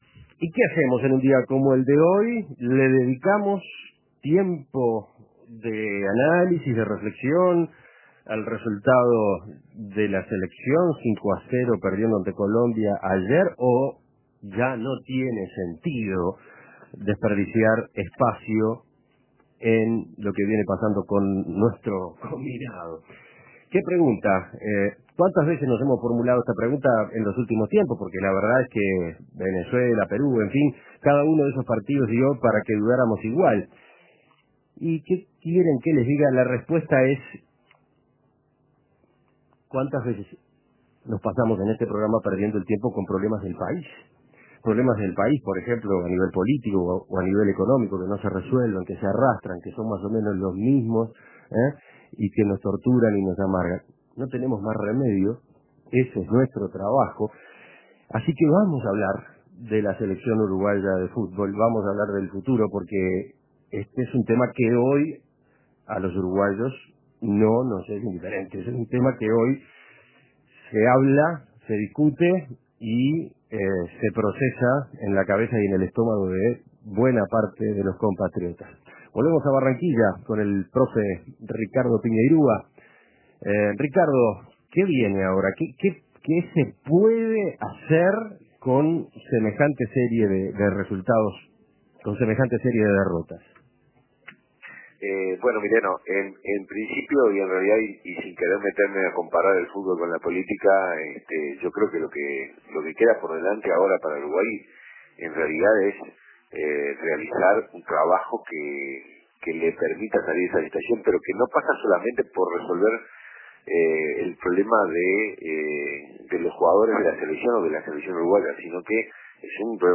Entrevistas Uruguay goleado Imprimir A- A A+ La celeste sólo cosecha derrotas.